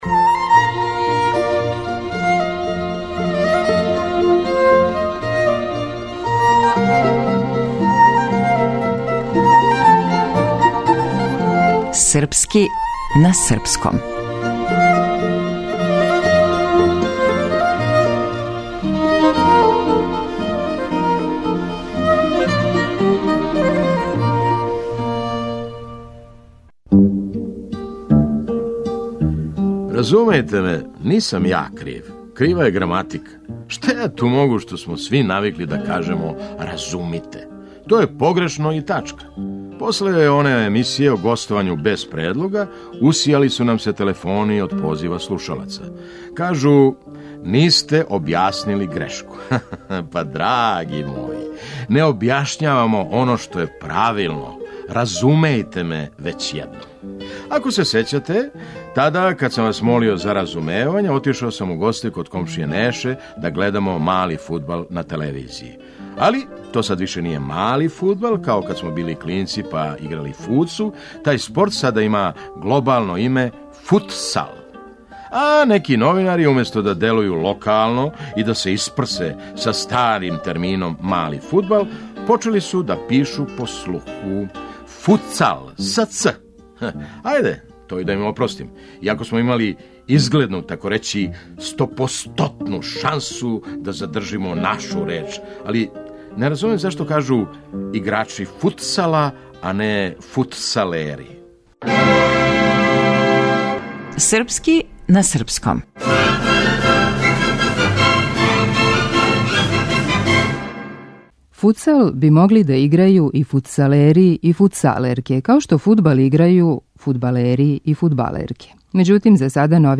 Драмски уметник - Феђа Стојановић